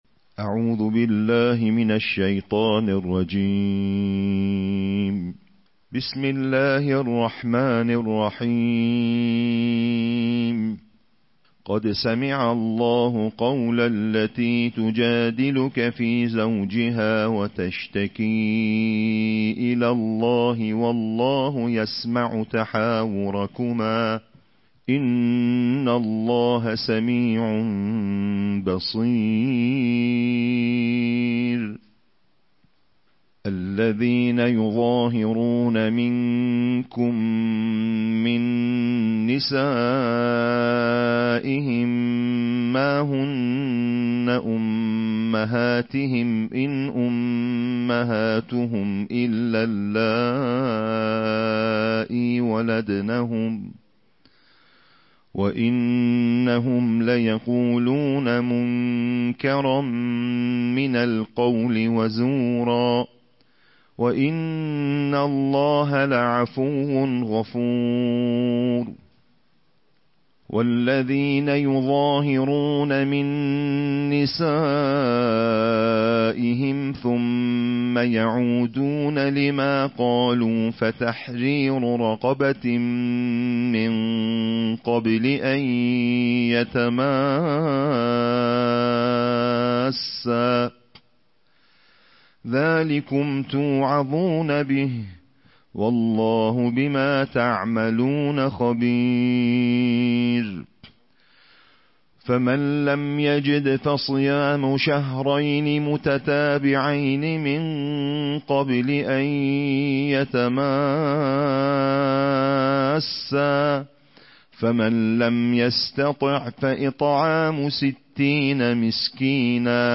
Қироати тартилӣ - ҷузъи 28-уми Қуръон бо садои қориёни байнулмилалӣ + садо